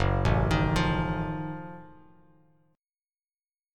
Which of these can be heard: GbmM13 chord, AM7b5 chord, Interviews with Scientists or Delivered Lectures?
GbmM13 chord